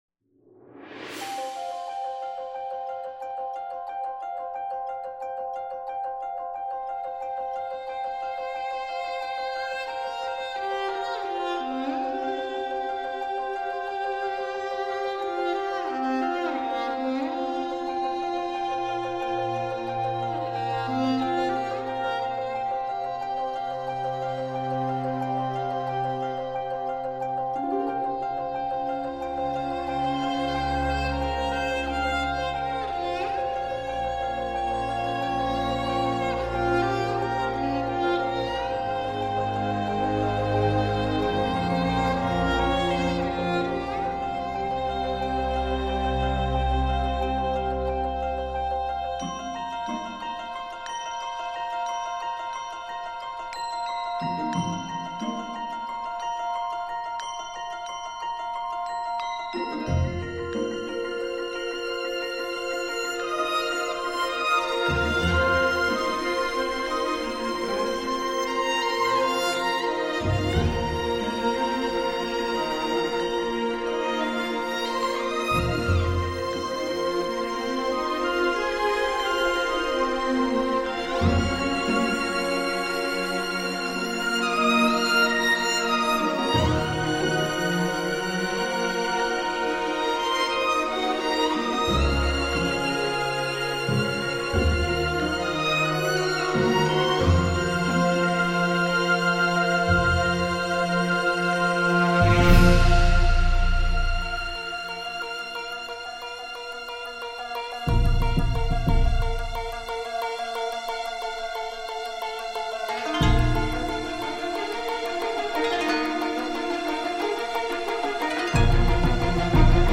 les cordes de l’orchestre de Budapest